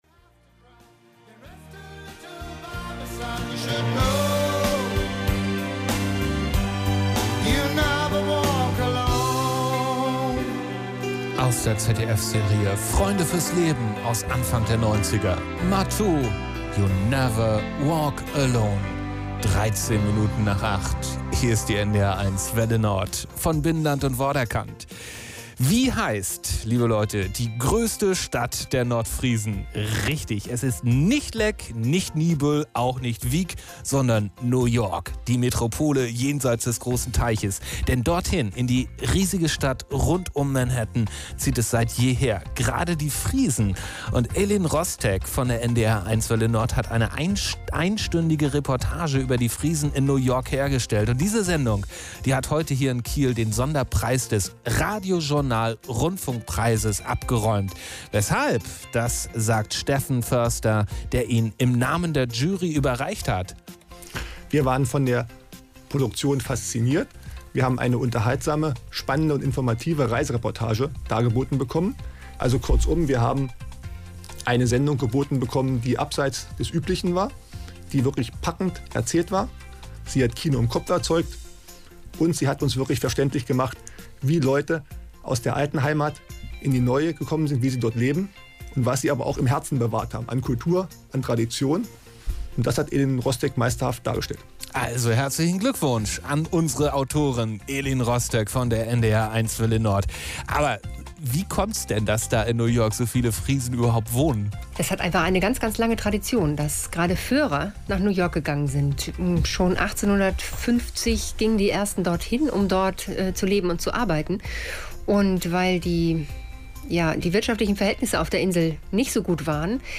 Hörfunkbeitrag der NDR 1 Welle Nord
zur Preisverleihung am 22. März 2009